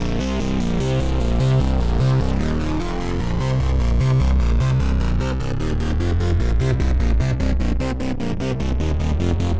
I heard it today in the chorus effect of FAW Circle VSTi.
About 4s in, it reaches maximum “in my head”-ness. :)/> I think the effect is easier to hear with headphones.
1-inmyhead.wav